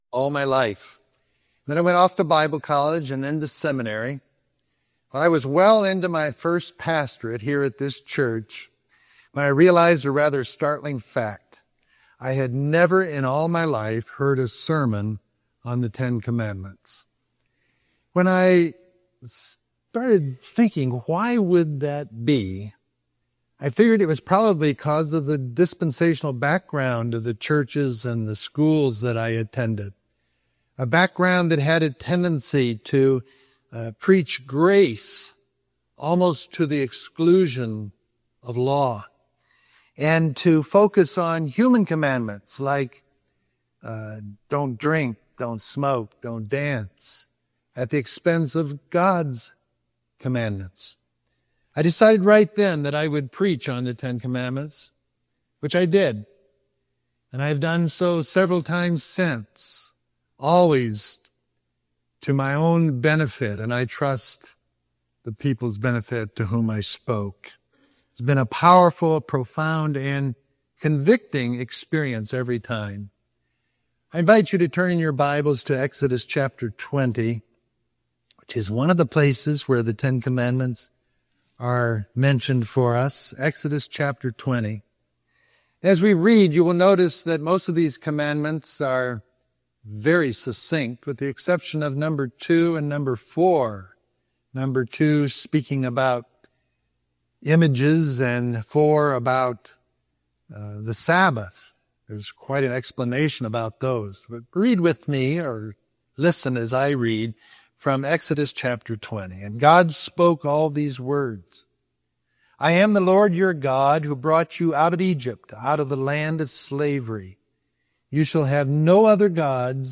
However, I was well into my first pastorate here when I realized a rather startling fact: I had never in my entire life heard a sermon on the Ten Commandments!